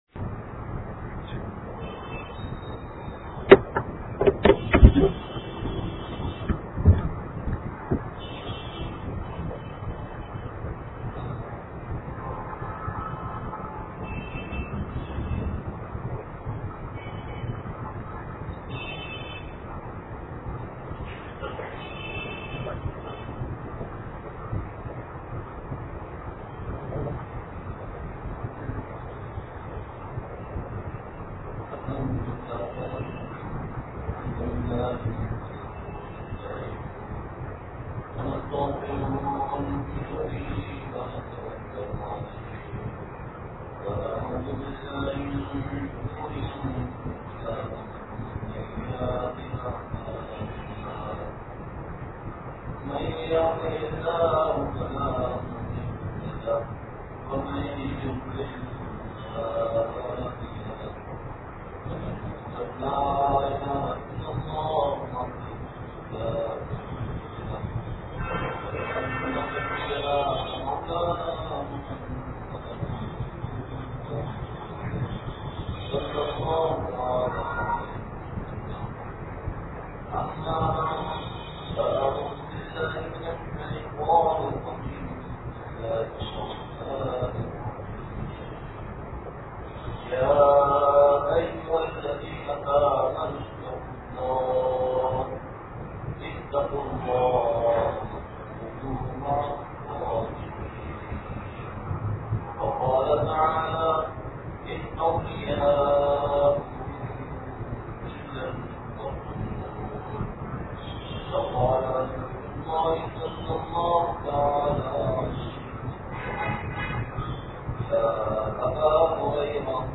بیان بعد نماز عصرمدنی مسجد کپڑا مارکیٹ نوابشاہ سندھ